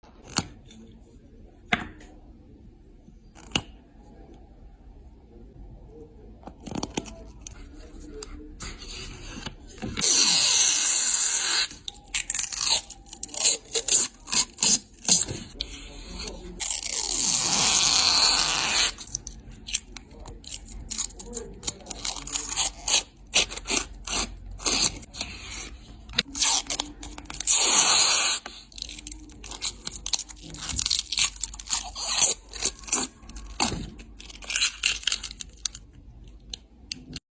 • Category: ASMR sounds